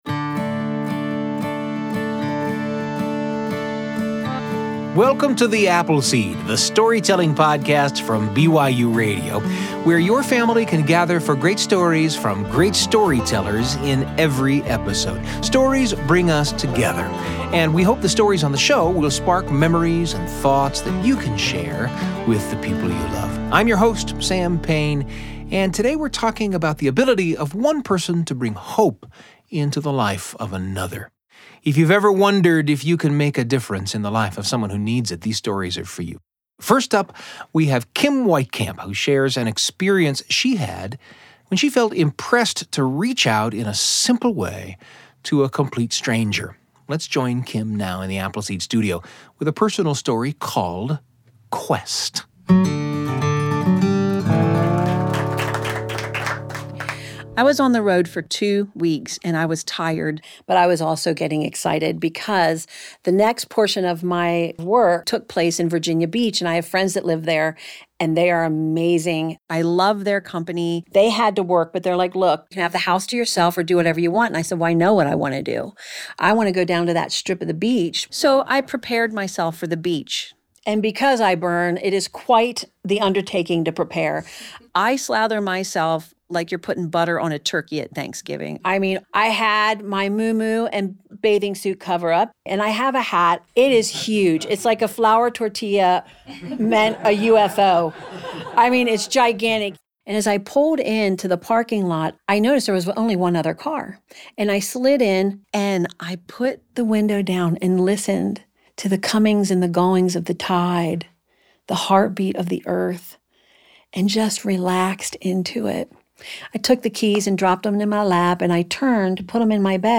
S10 E10: Hope - Stories for the Whole Family